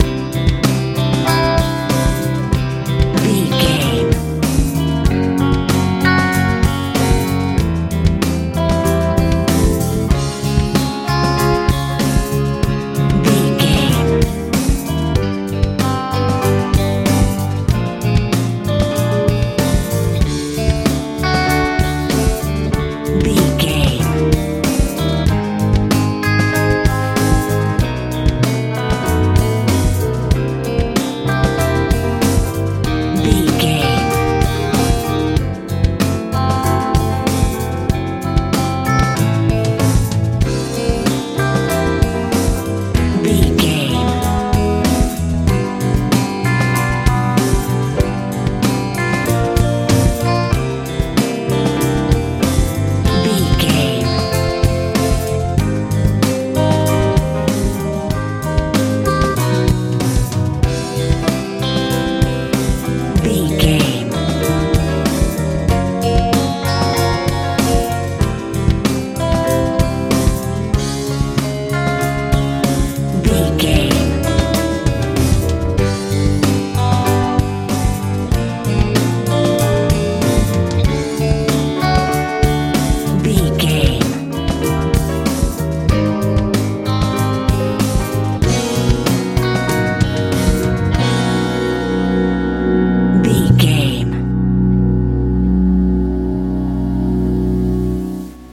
lite pop feel
Ionian/Major
light
mellow
piano
electric guitar
bass guitar
drums
80s
90s